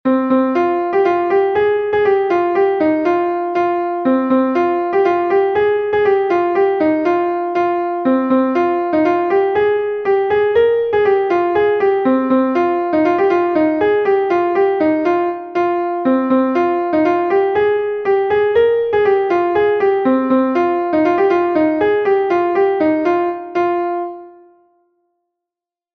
Gavotenn Karnassen est un Gavotte de Bretagne